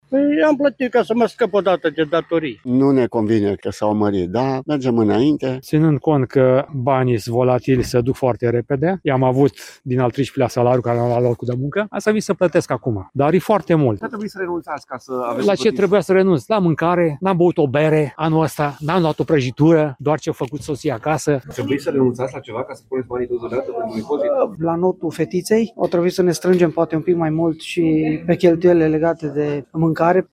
19ian-15-CORESP-AR-voxuri-oameni-la-plata-impozitelor-.mp3